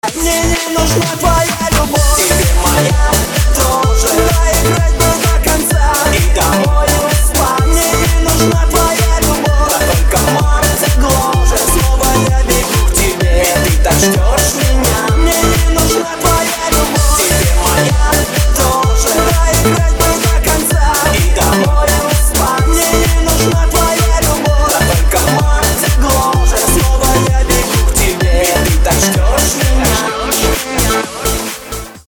• Качество: 320, Stereo
мужской вокал
веселые
dance